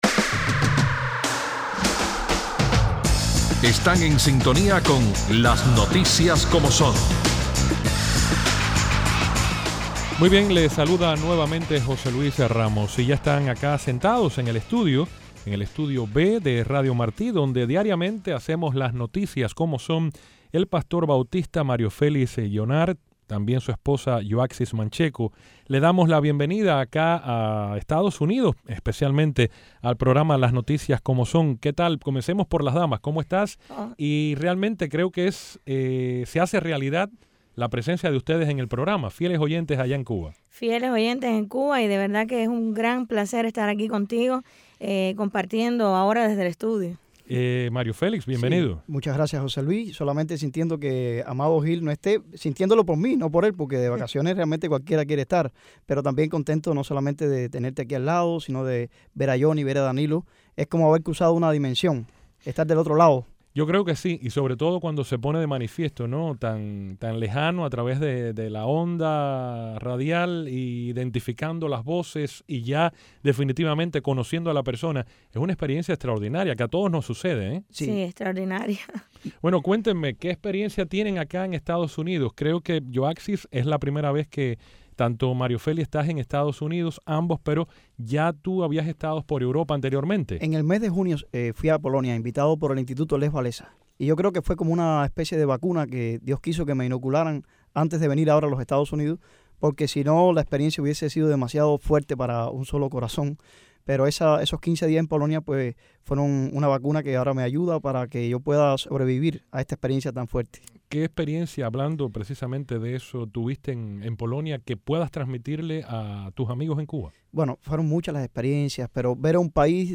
Hoy en un programa especial, con nosotros en el estudio